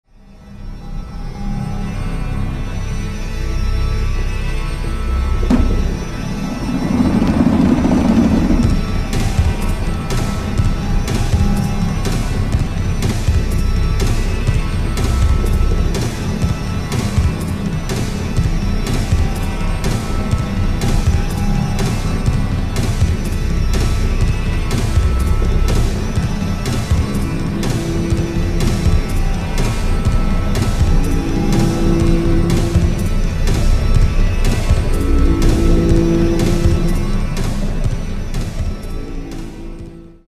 remains a brilliant opus of exceptional new age arrangements
enveloping an ambience all of its own.